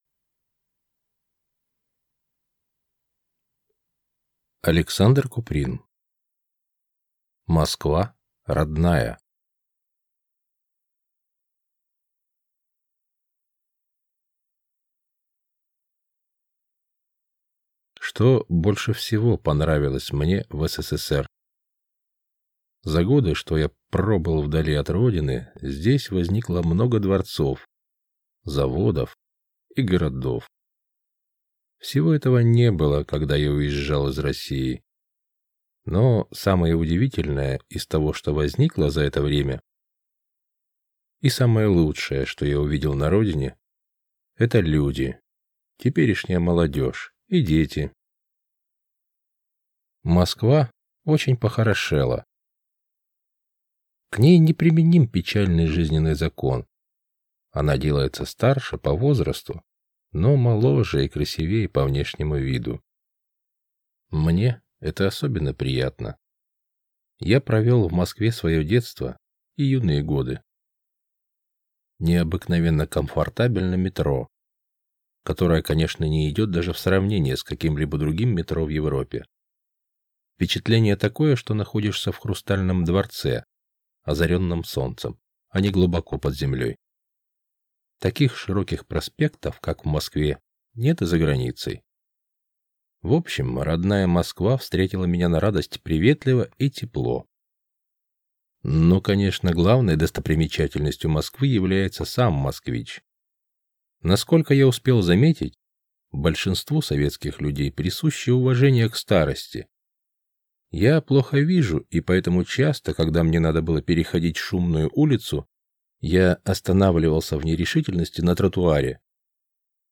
Аудиокнига Москва родная | Библиотека аудиокниг
Прослушать и бесплатно скачать фрагмент аудиокниги